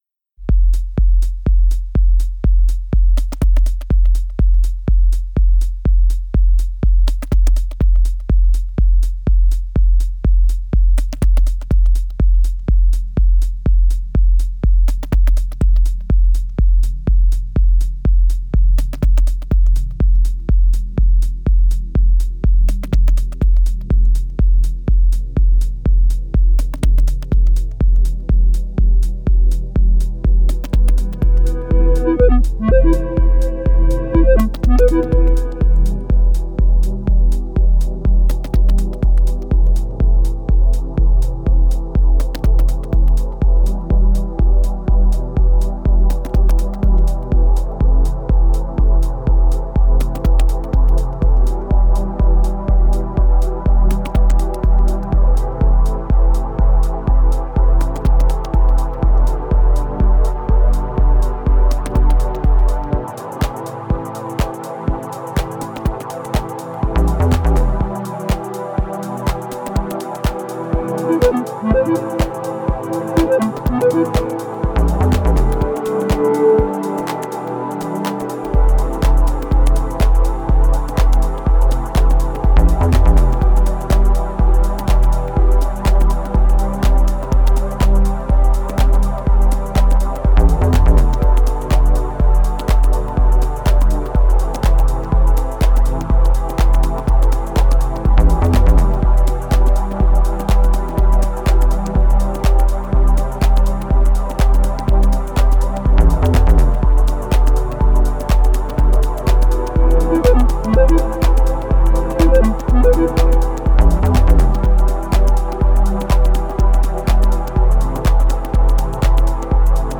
Genre: Deep Techno/Ambient/Dub Techno.